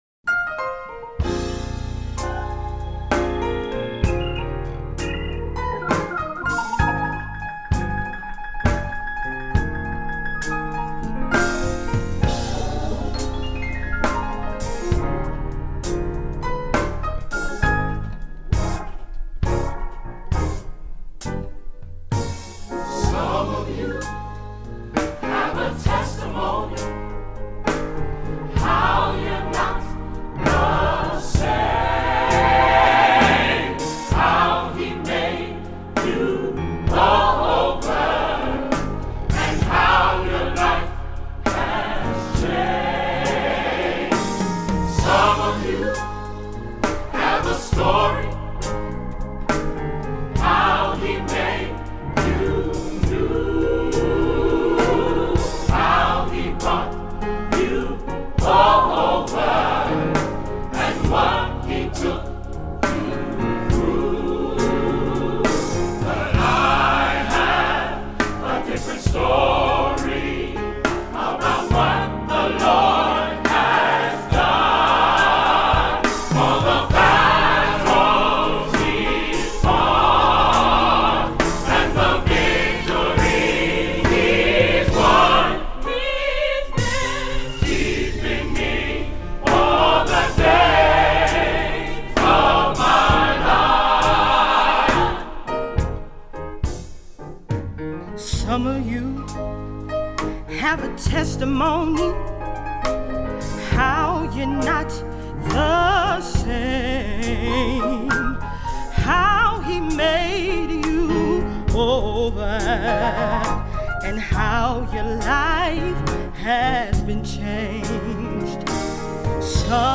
Voicing: SATB; Solo